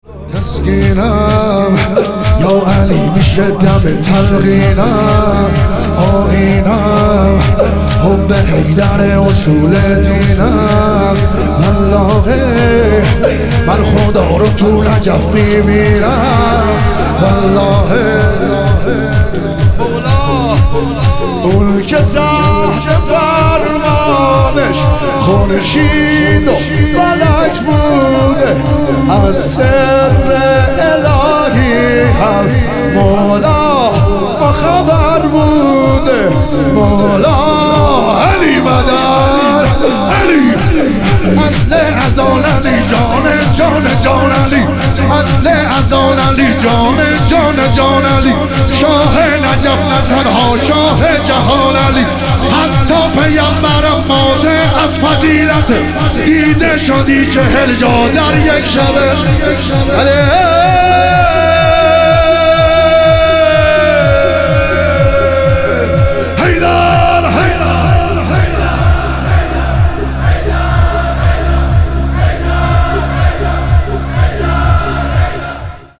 تخریب بقیع - شور - 8 - 1403